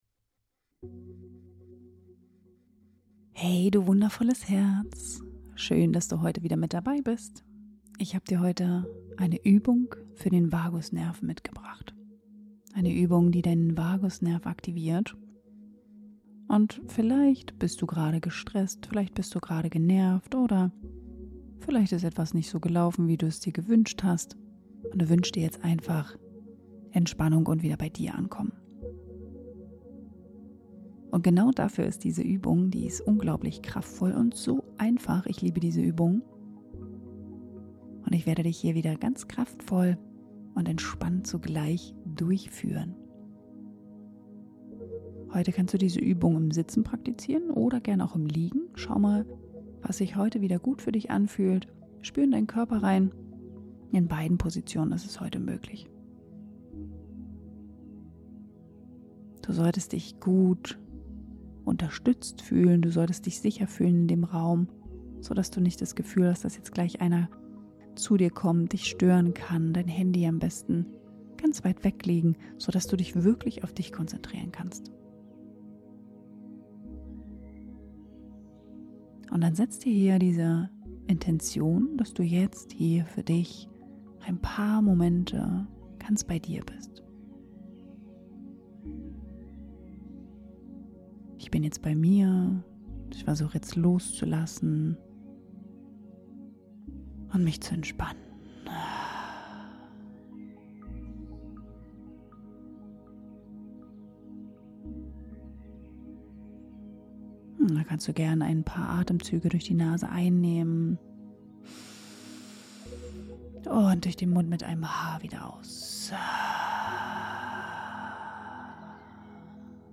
Atemmeditation zur Vagus-Nerv-Aktivierung - Finde inneren Halt & baue Stress ab ~ Atemgesundheit - Balance statt Hektik Podcast